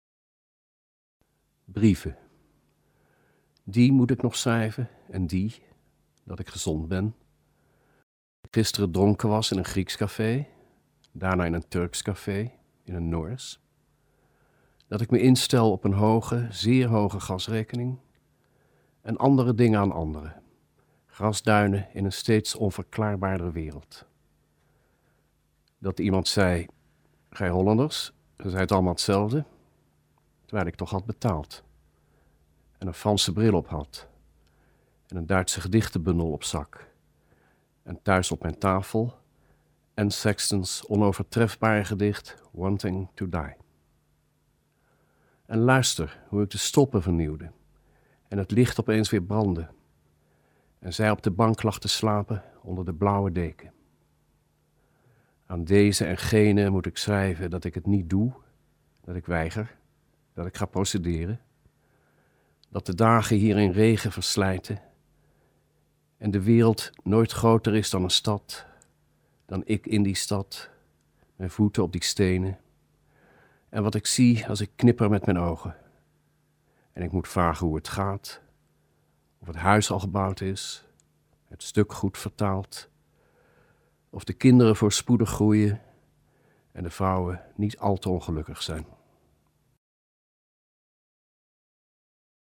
Remco Campert leest 'Brieven' voor - Remco Campert lee 'Cartas'